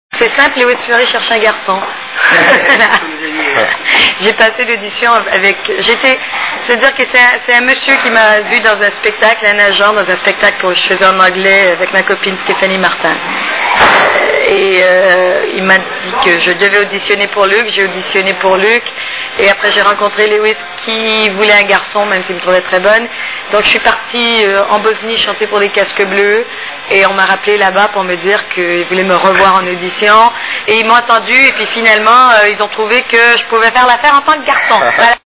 STARMANIA...Interview de Jasmine Roy !!!
( Casino de Paris, Hall d’entrée, 06/02/2000 )